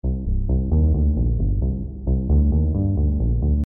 漩涡中的滚动 环境低音
Tag: 0 bpm Techno Loops Synth Loops 621.75 KB wav Key : Unknown